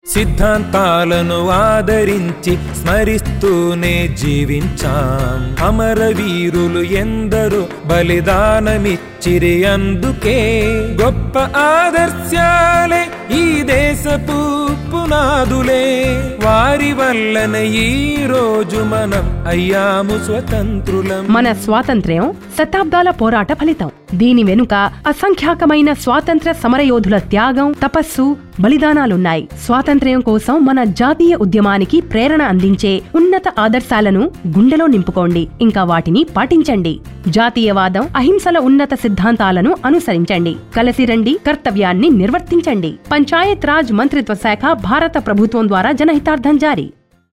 224 Fundamental Duty 2nd Fundamental Duty Follow ideals of the freedom struggle Radio Jingle Telugu